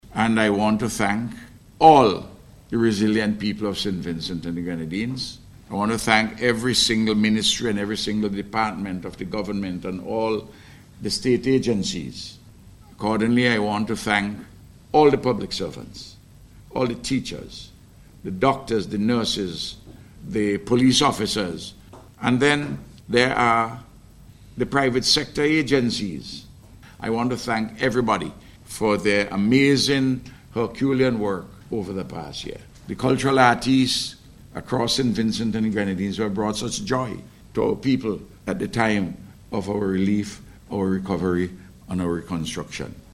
The Prime Minister was delivering the feature address at the launch of the Beryl Emergency Resilient Recovery (BERRy) Project yesterday at the NIS Conference Room.